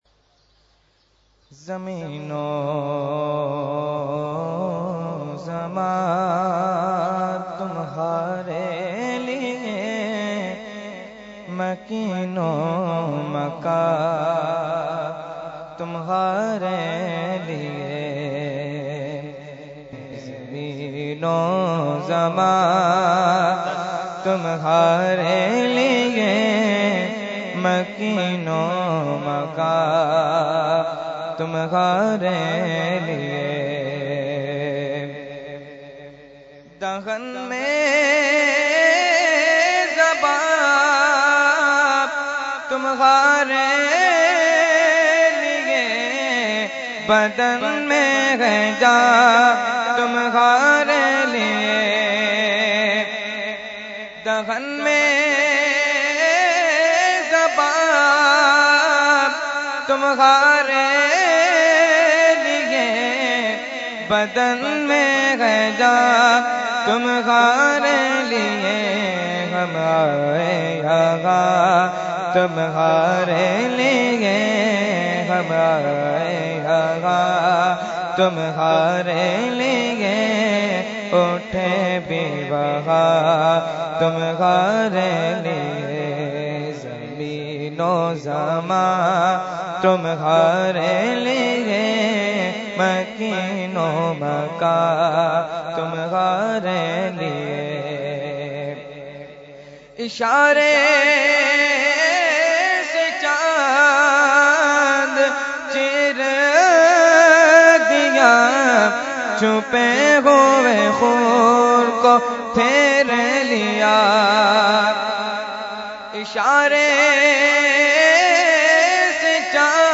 Category : Naat | Language : UrduEvent : Urs Qutbe Rabbani 2017